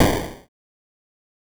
8bit Noise
8bit_FX_noise_01_03.wav